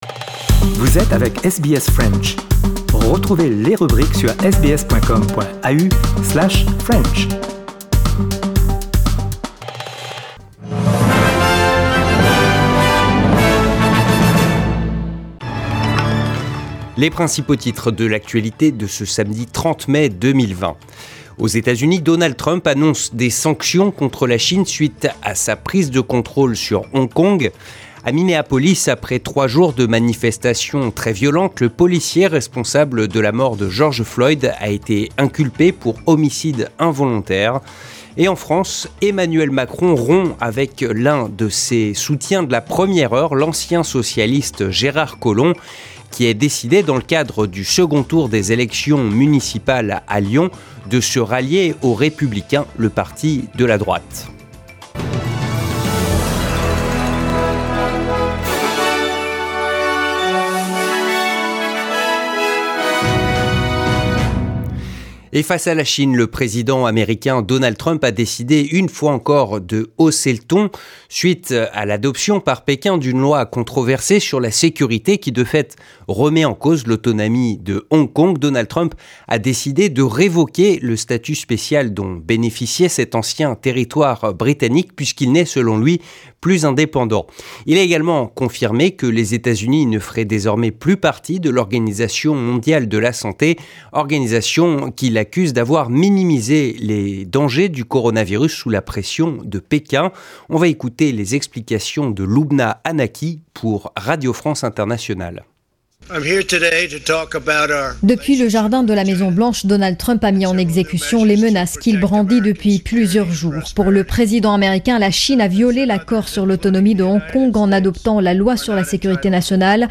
Le journal du 30 mai 2020